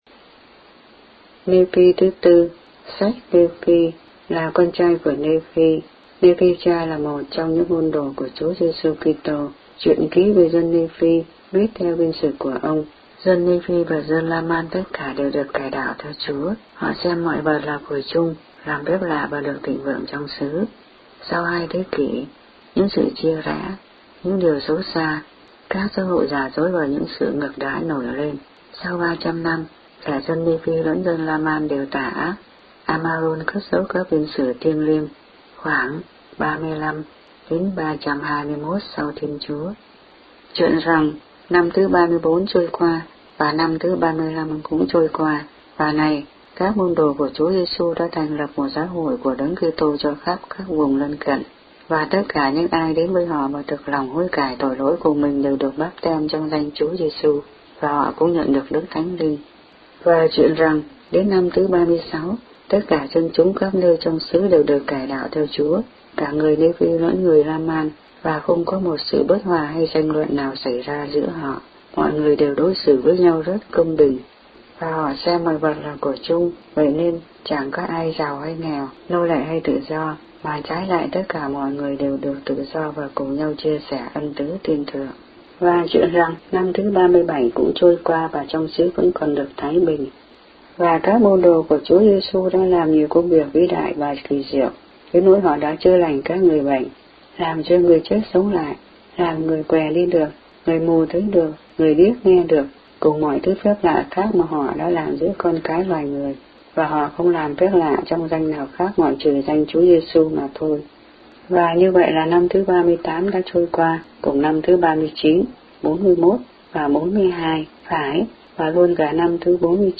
The Book of Mormon read aloud in Vietnamese.